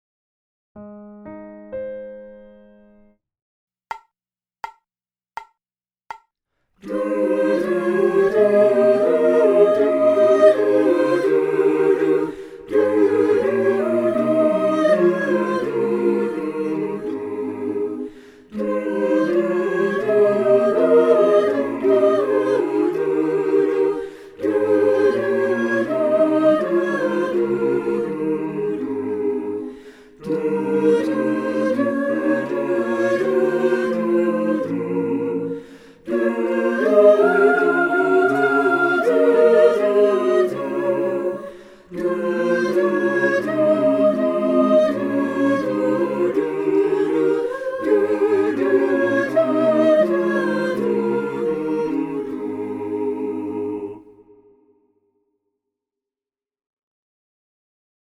Full-Mix
Full-Mix.mp3